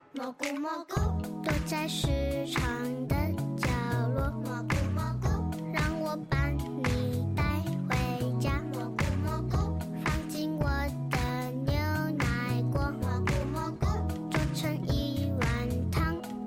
學校鐘聲投票